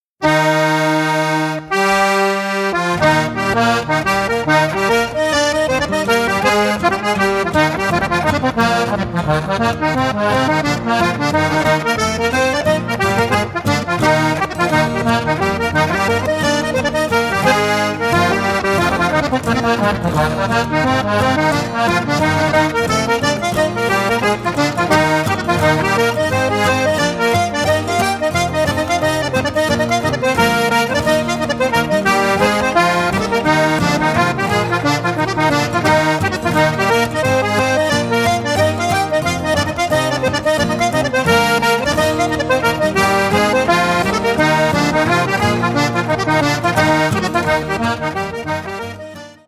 Straight down the line Irish traditional accordion music